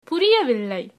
Recordings are in male and female voices.